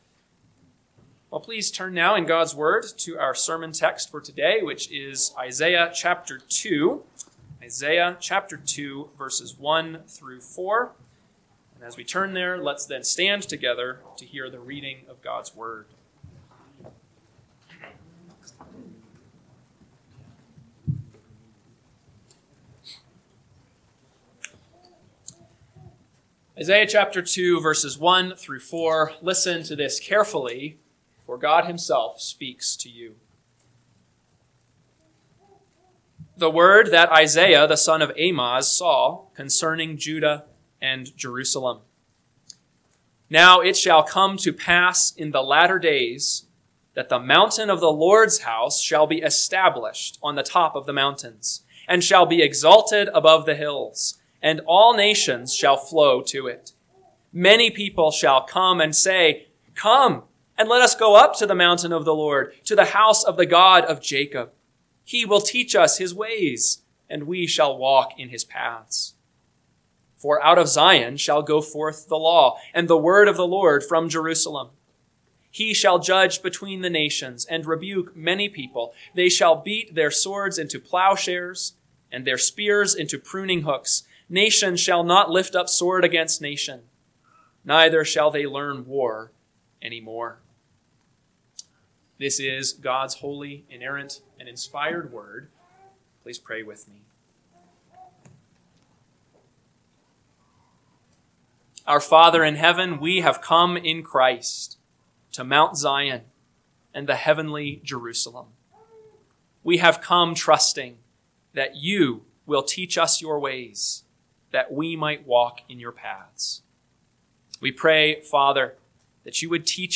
AM Sermon – 10/19/2025 – Isaiah 2:1-4 – Northwoods Sermons